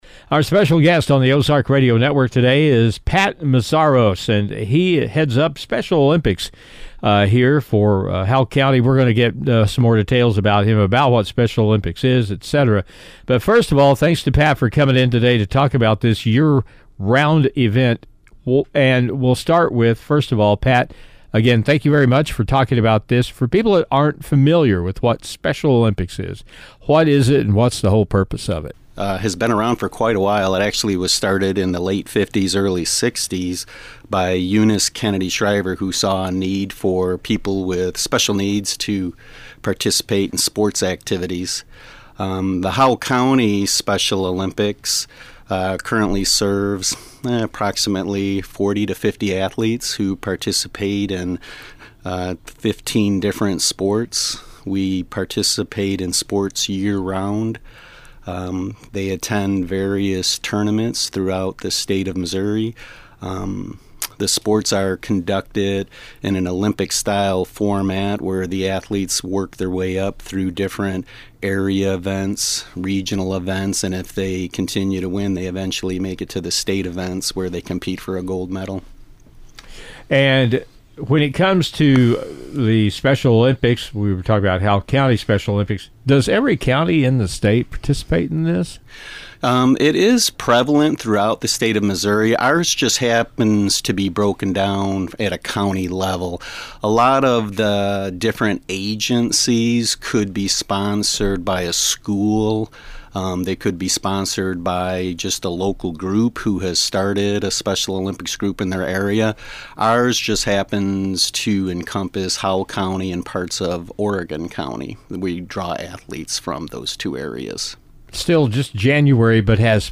Double H Interviews